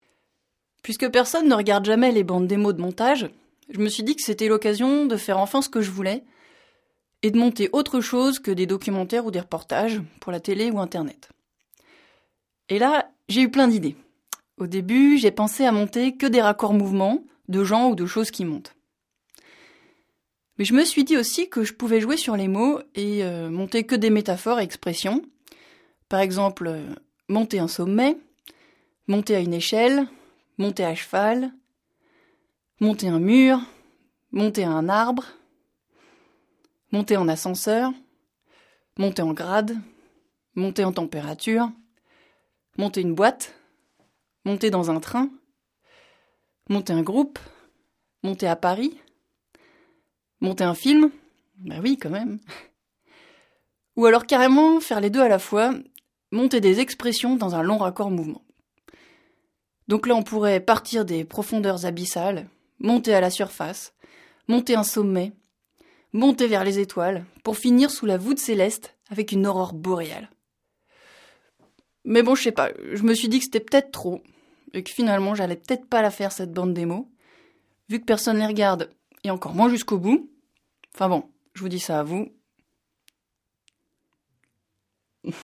Des voix-off